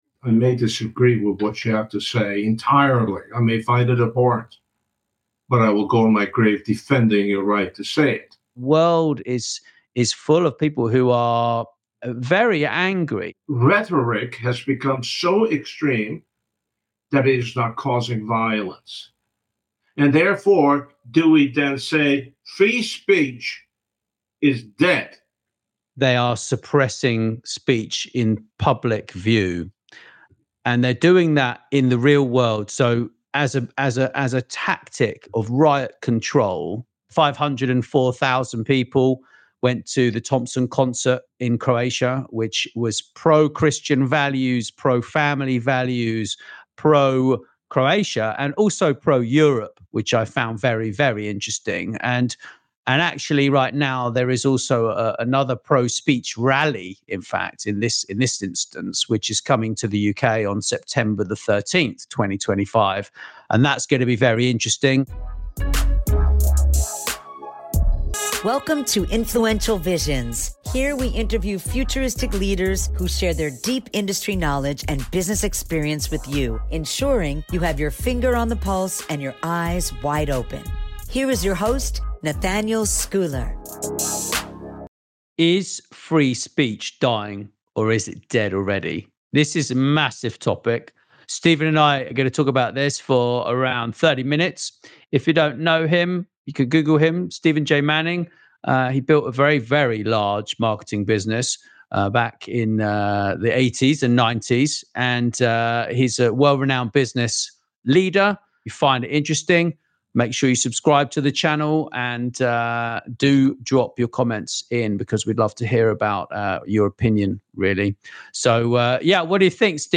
Free Speech in the Digital Age: A Critical Conversation